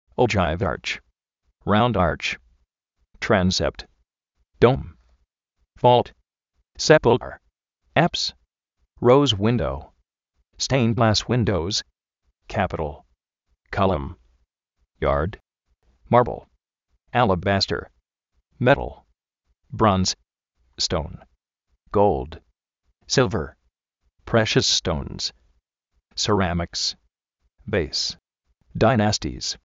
oyáiv ar:ch, ráund ar:ch, tránsept, dóum, vólt,
sépulker, áps,
róus-uíndow,
stéind glas uíndows,
kápital, kálom, iárd
márbl, álabaster, métal, bróuns, stóun, góuld, sílver, préshas stóuns, serámiks, véis